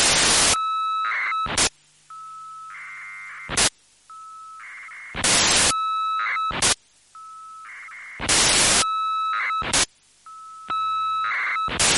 UnknownChirp2_Sound.mp3